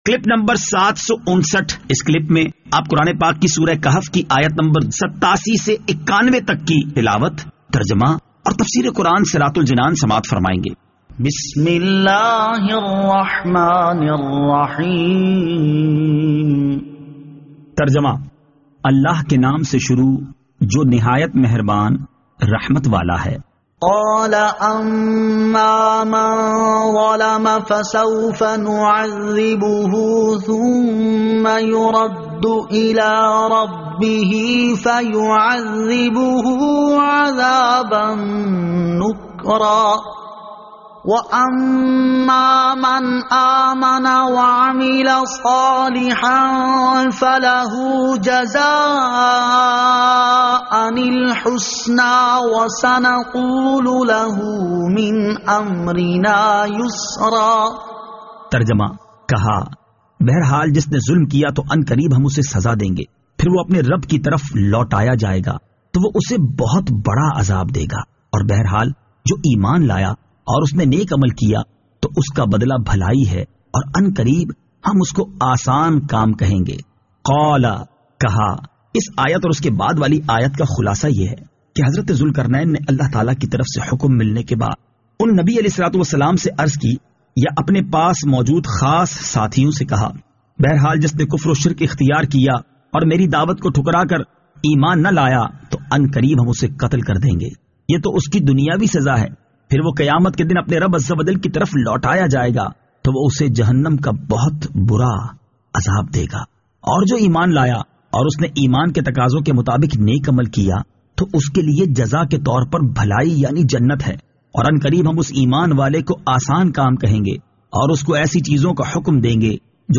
Surah Al-Kahf Ayat 87 To 91 Tilawat , Tarjama , Tafseer